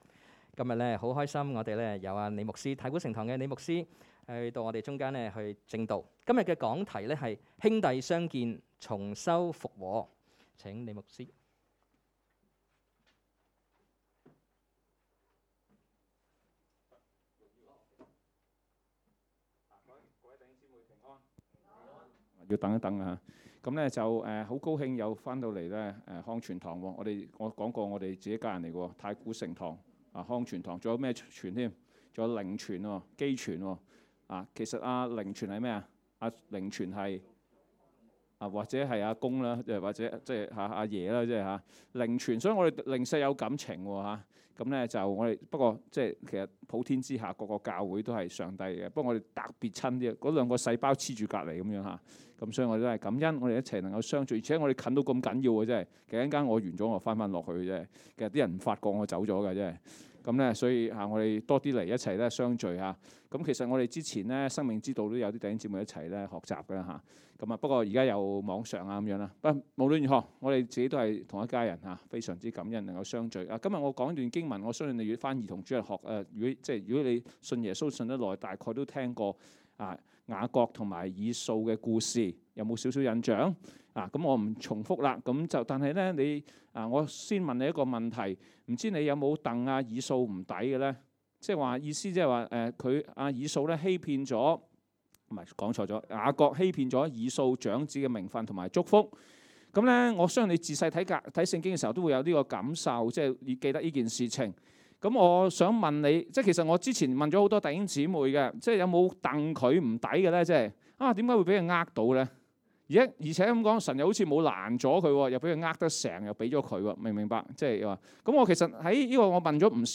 2022 年 7 月 2 日及 3 日崇拜
崇拜講道